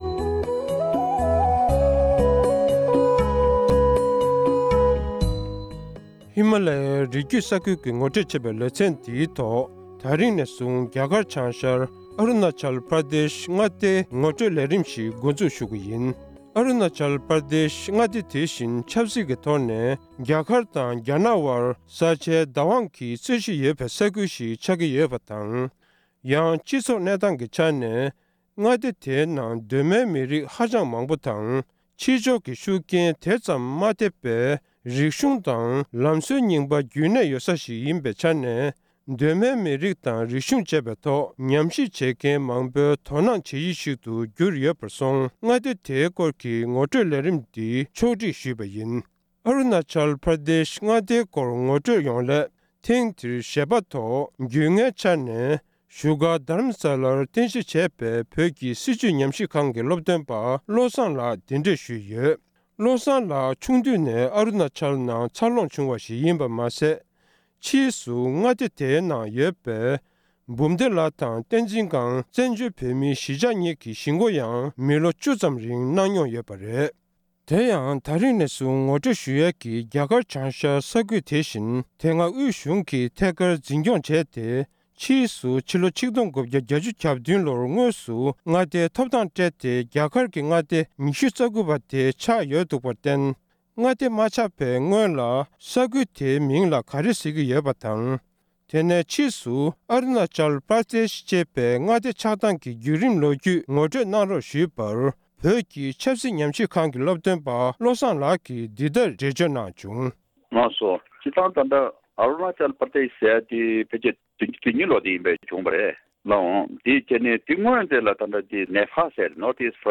སྒྲ་ལྡན་གསར་འགྱུར། སྒྲ་ཕབ་ལེན།
བཀའ་འདྲི་ཞུས་པ་ཞིག